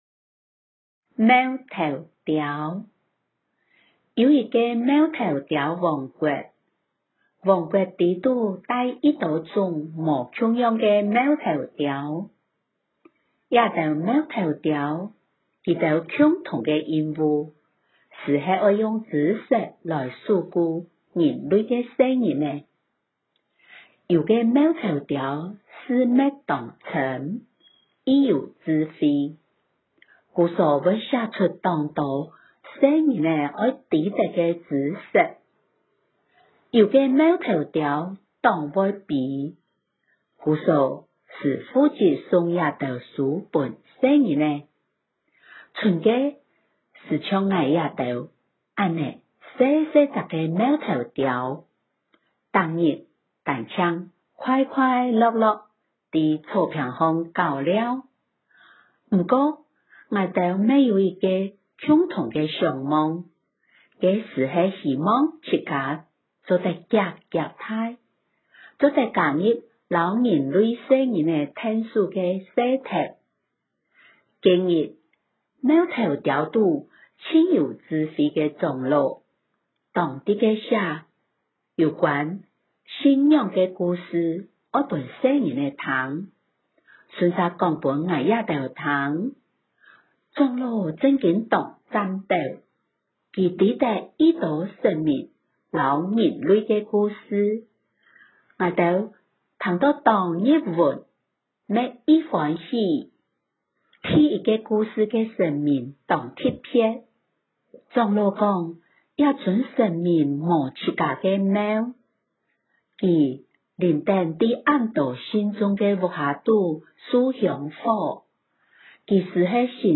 貓頭鳥_教學錄音檔（四縣腔）.m4a (另開新視窗)
貓頭鳥_教學錄音檔-四縣腔.m4a